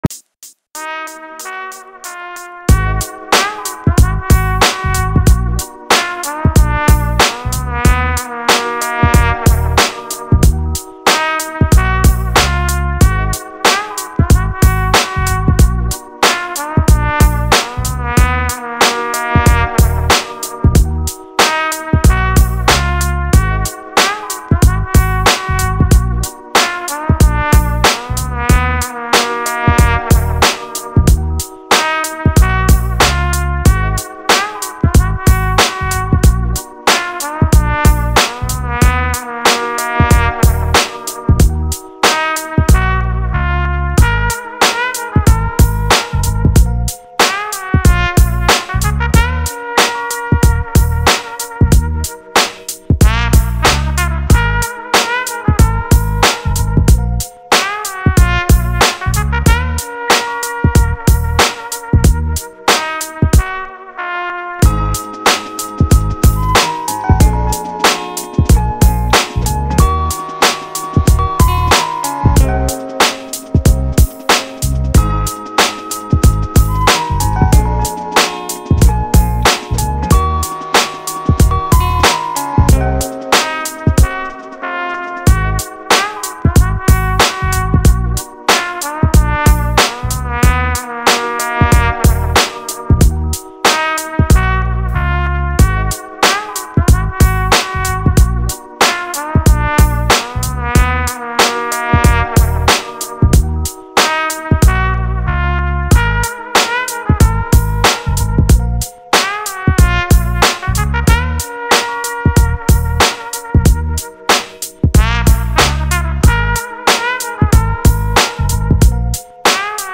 Write This Down Instrumental.mp3